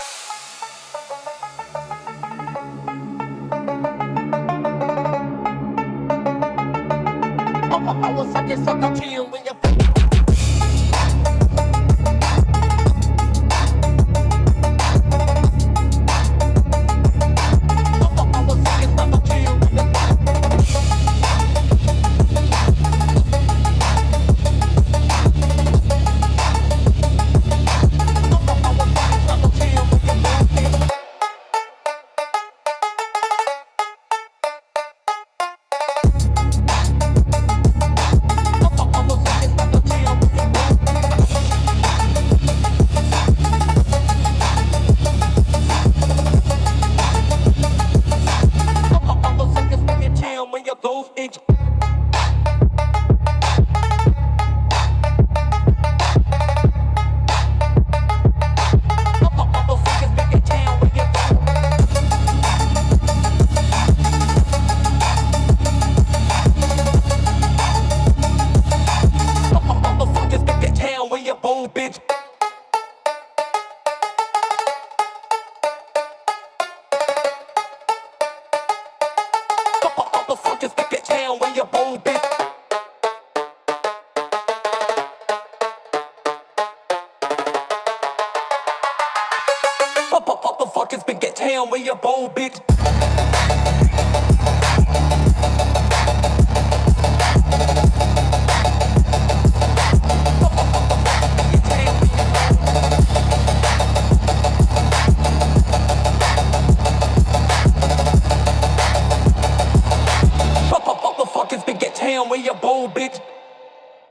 Genre: Phonk Mood: Dark Editor's Choice